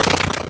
Sound effect of "Break Block" in Super Smash Bros. Melee.
SSBM_Break_Block.oga